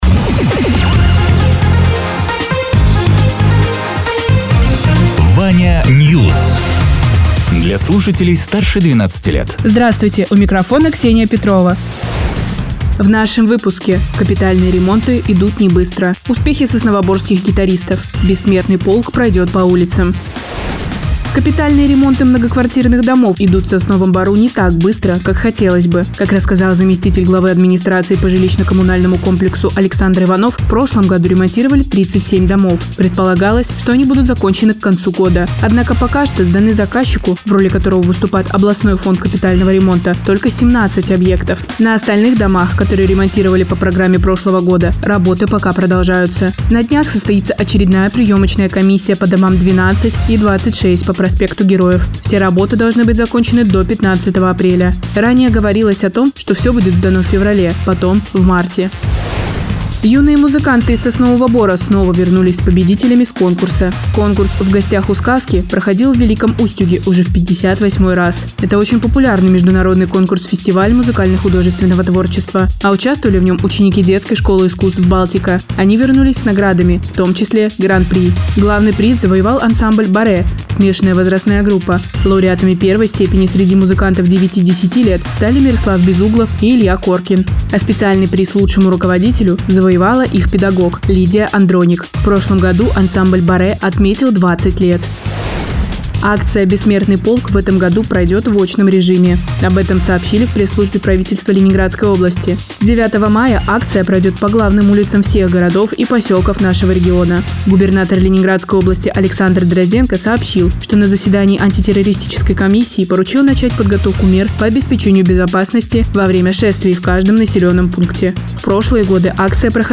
Радио ТЕРА 02.04.2026_12.00_Новости_Соснового_Бора